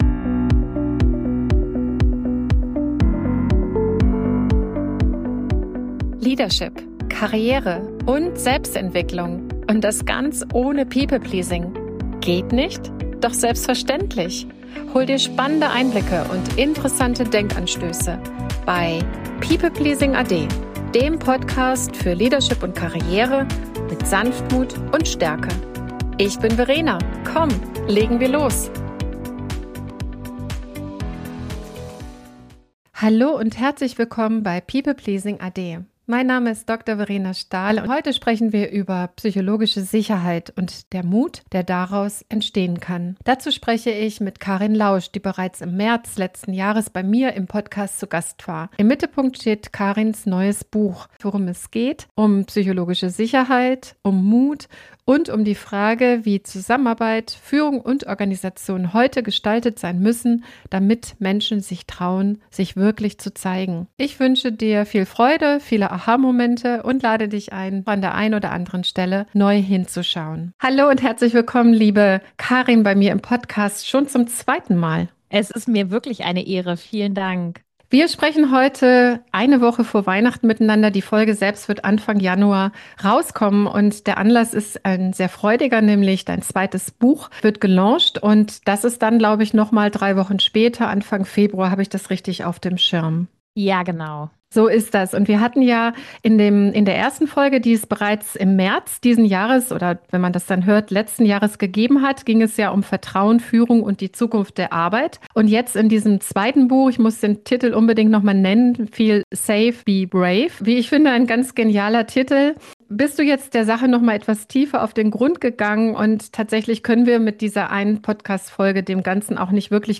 Ein tiefgehendes Gespräch